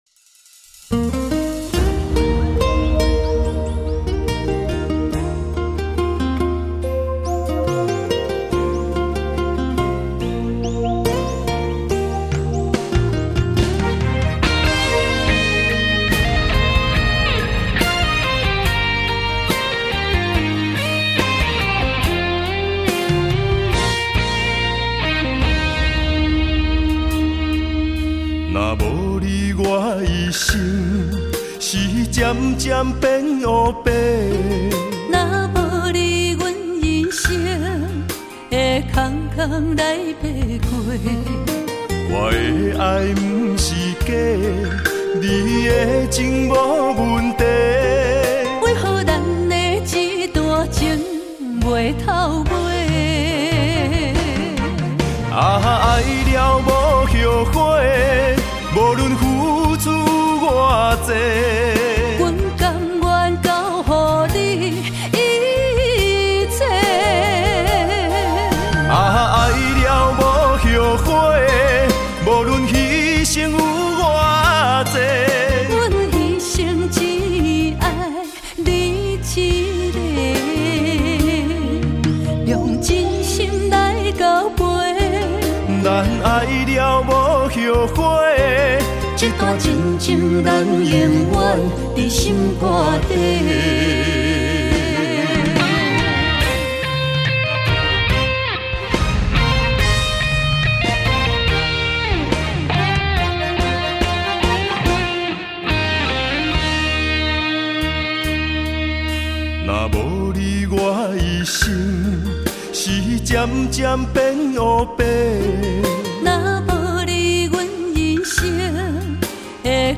音乐类型：台语歌曲